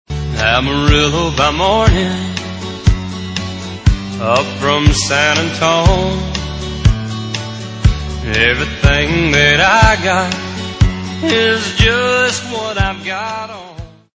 Tags: ringtones tones cell phone music melody country songs